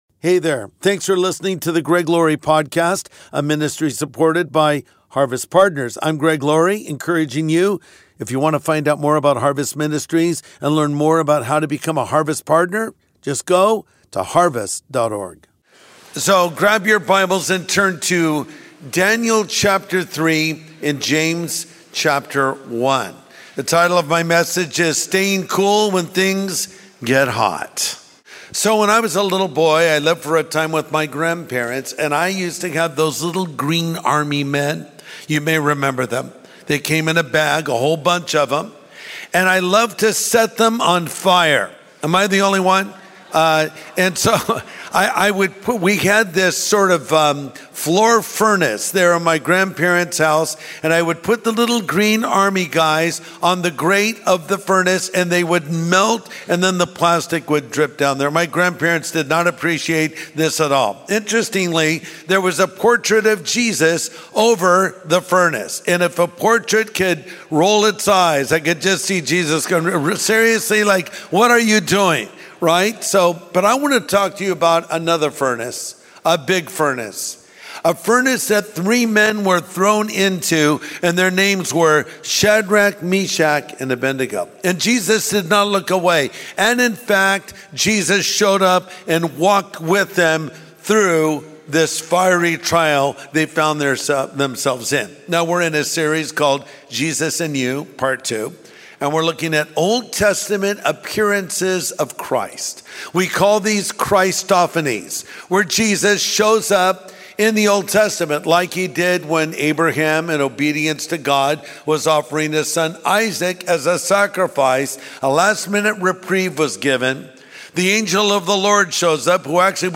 Pastor Greg Laurie shares more in his message focused on Shadrach, Meshach, and Abednego.